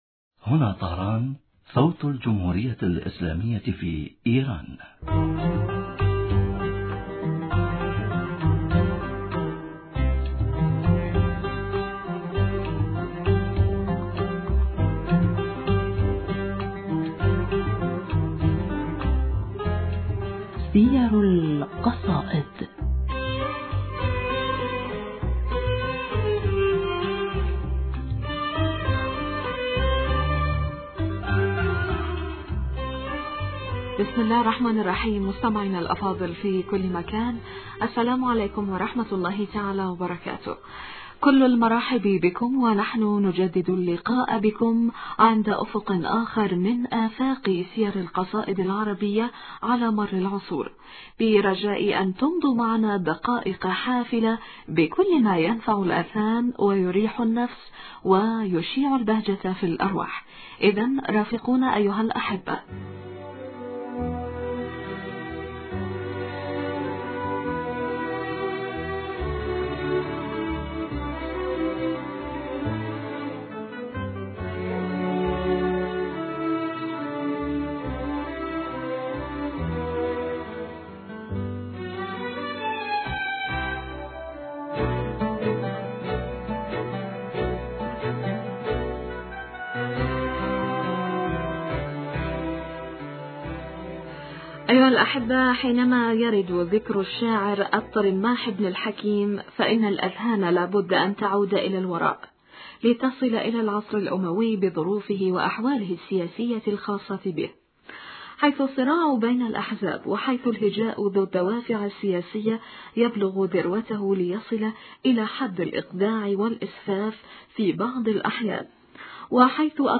المحاورة: